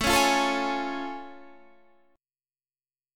A+M7 Chord
Listen to A+M7 strummed